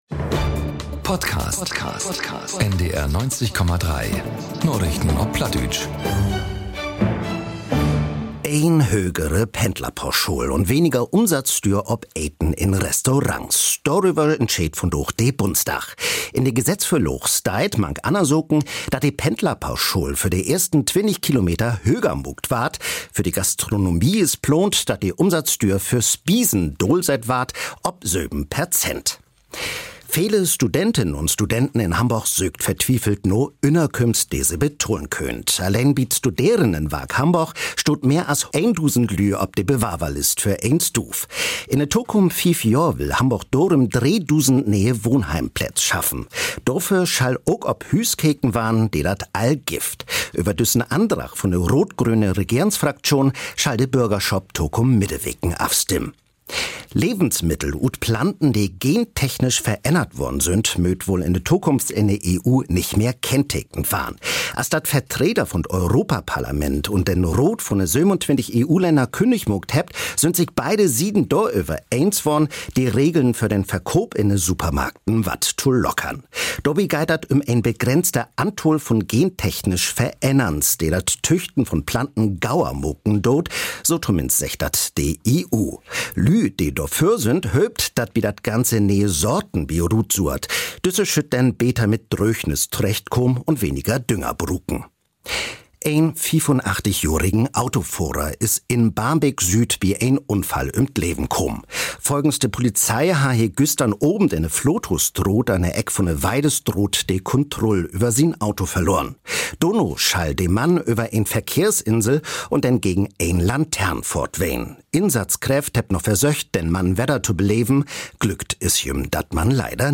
aktuellen Nachrichten auf Plattdeutsch.